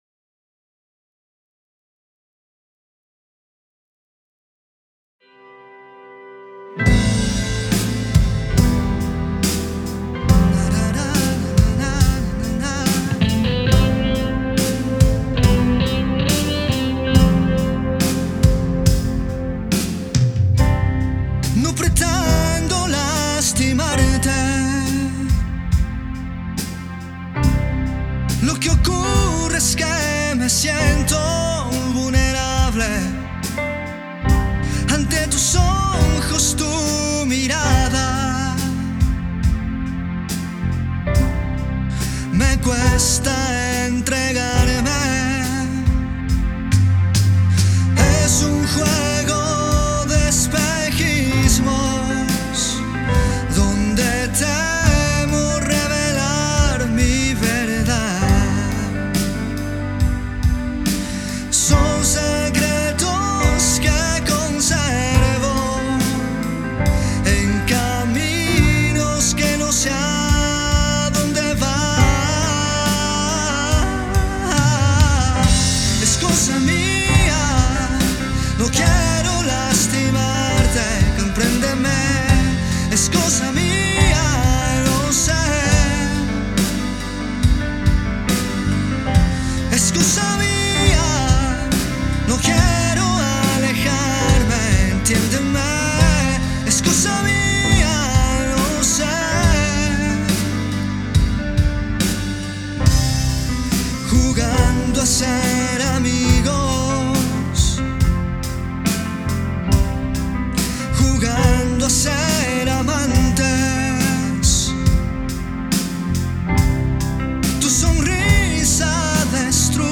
una balada cargada de emociones intensas.